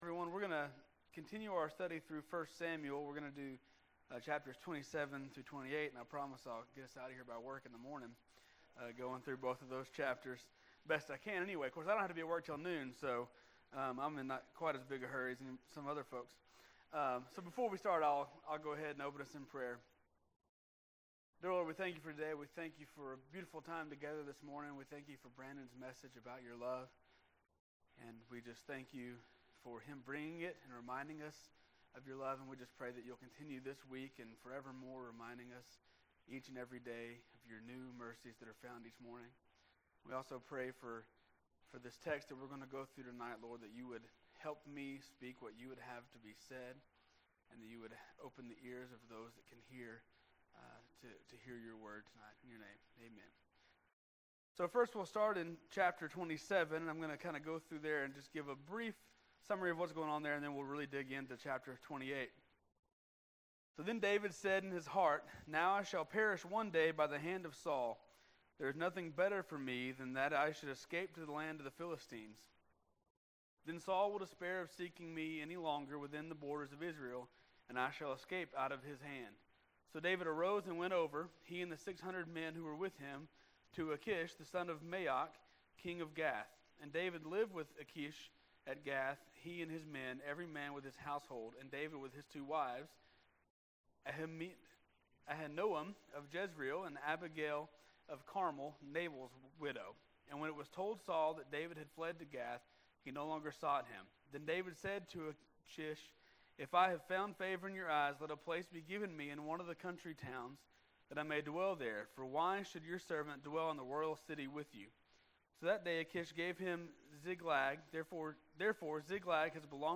This was the next message in multi-part sermon series on the book of 1st Samuel.